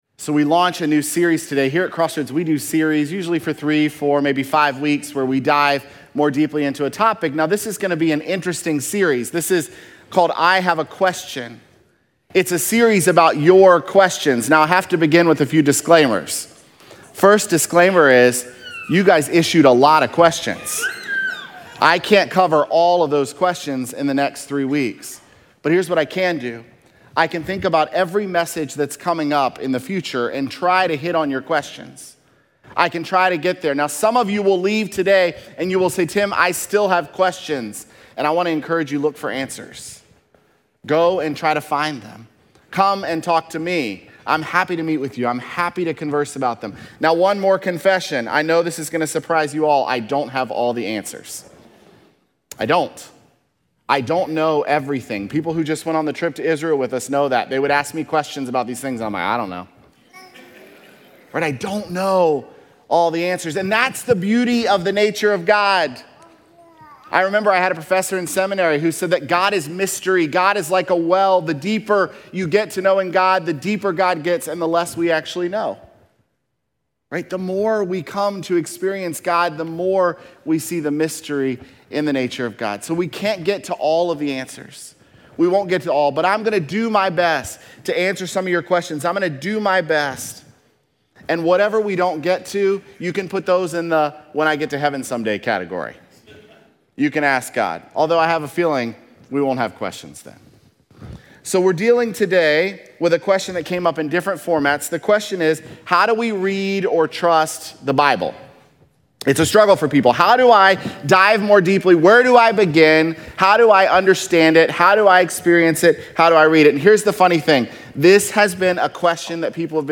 may7sermon.mp3